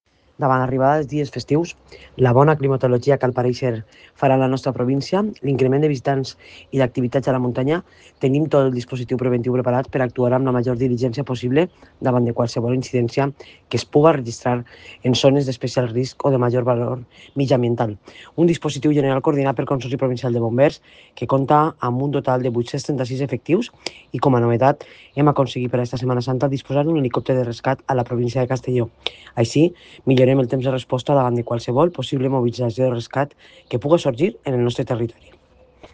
Presidenta-Marta-Barrachina-dispositivo-Semana-Santa.mp3